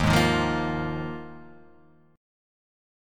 D#sus2sus4 chord